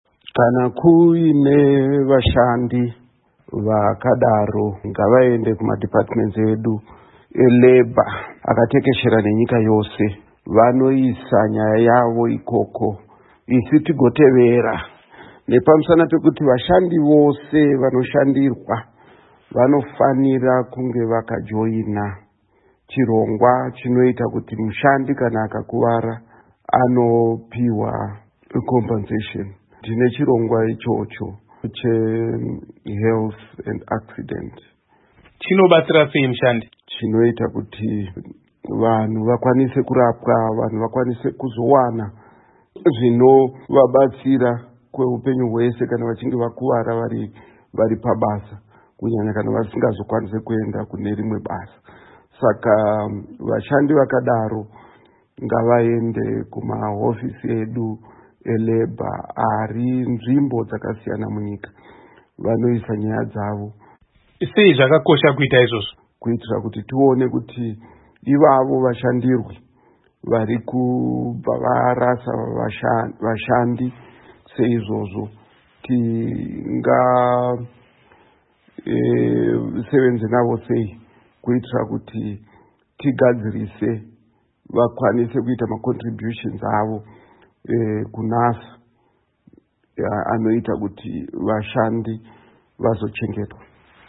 Hurukuro naMuzvinafundo Paul Mavima